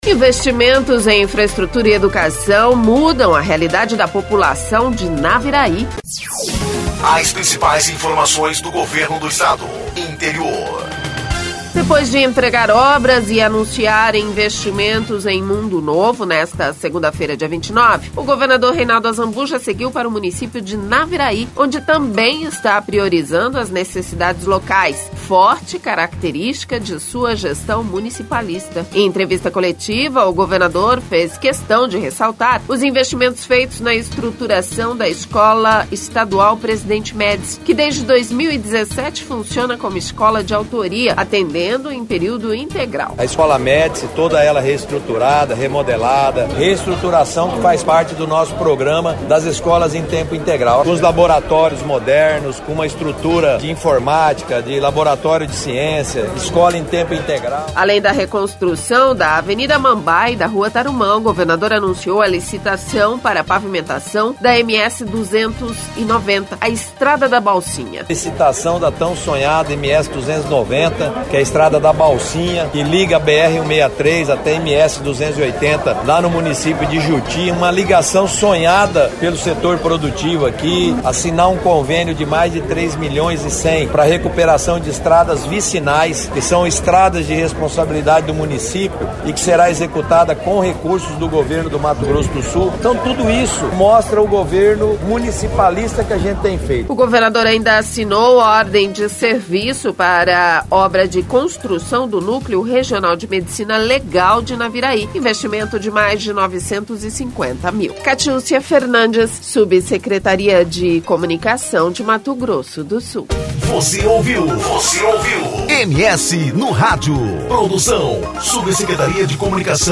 Em entrevista coletiva, o governador fez questão de ressaltar os investimentos feitos na estruturação da Escola Presidente Médici, que desde 2017 funciona como Escola de Autoria, atendendo em período integral.